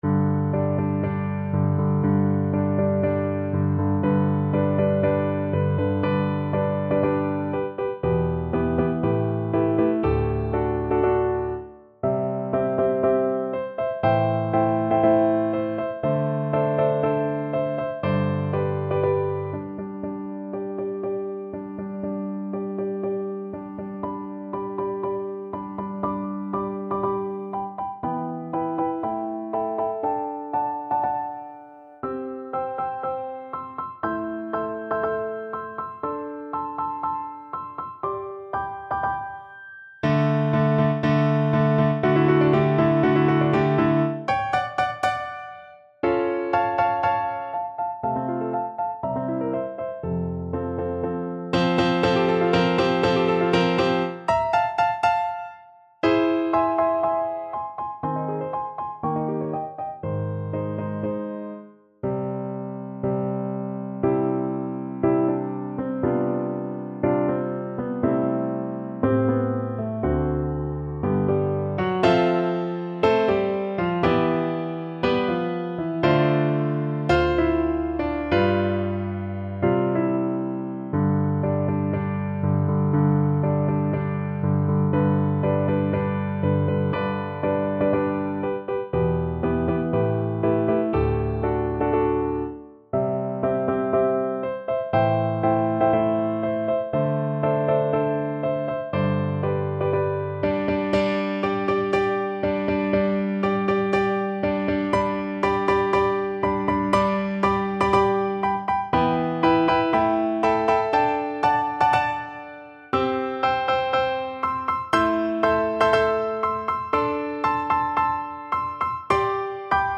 G major (Sounding Pitch) (View more G major Music for Flute )
~ = 120 Tempo di Marcia un poco vivace
Classical (View more Classical Flute Music)